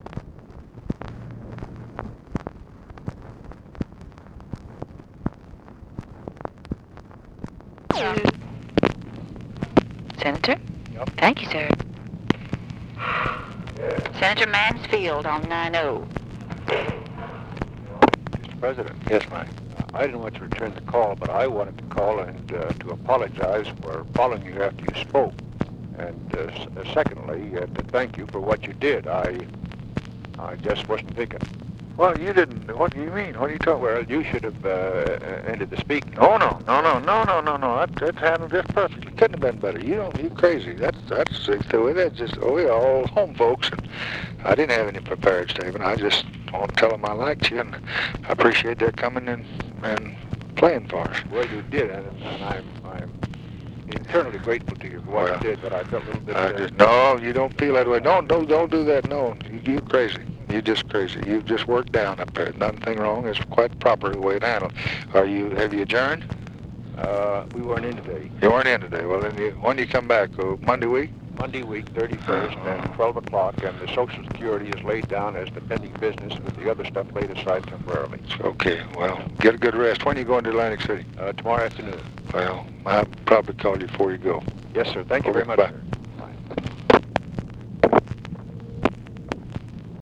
Conversation with MIKE MANSFIELD, August 22, 1964
Secret White House Tapes